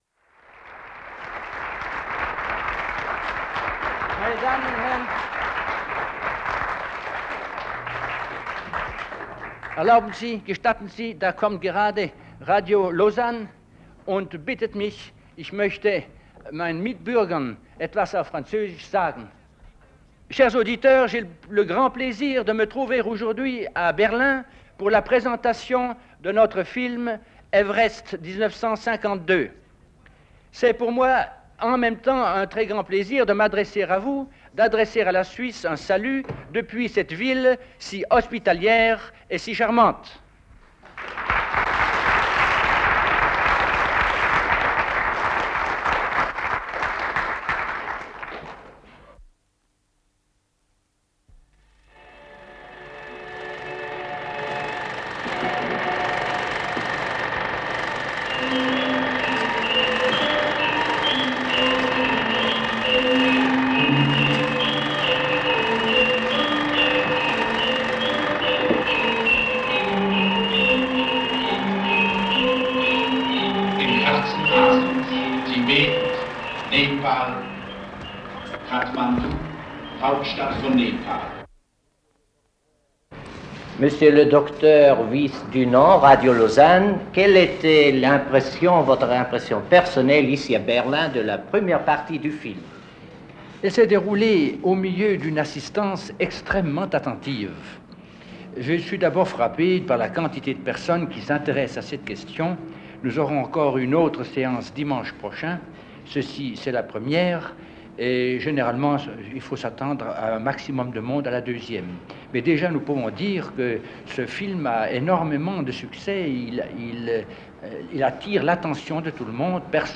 Bericht von Radio Beromünster zur Aufführung des Films «Mount Everest 1952» von André Roch und Norman G. Dyhrenfurth an den Filmfestspielen Berlin 1953.